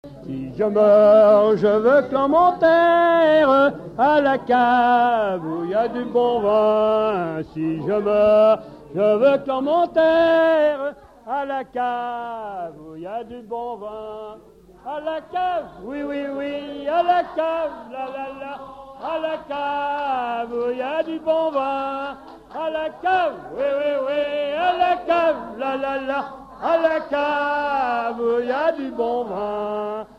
Veillée de collectage de chants et de danses
Pièce musicale inédite